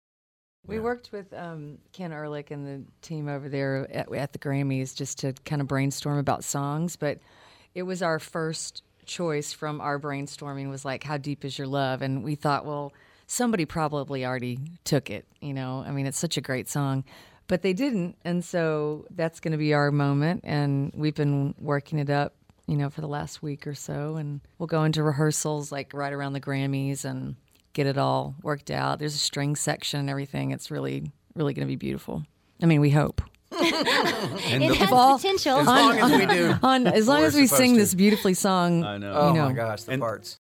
Audio / The members of Little Big Town talk about performing during the GRAMMY tribute to the Bee Gees.